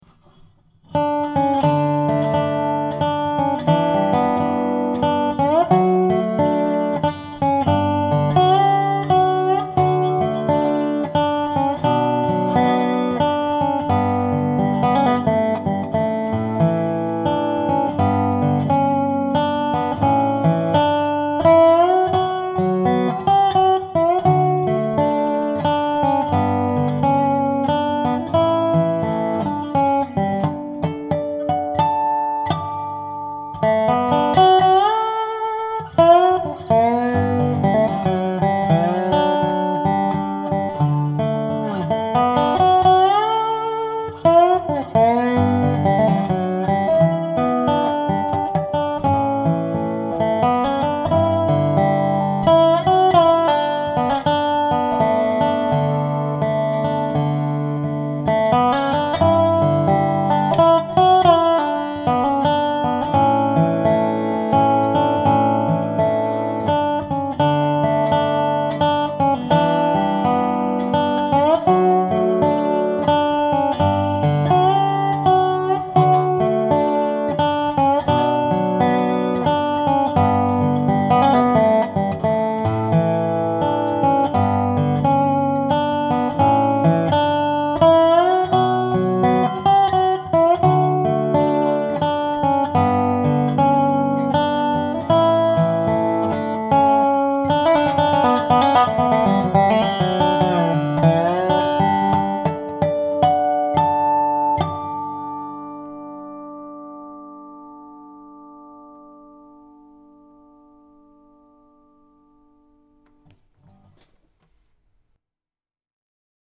Dobro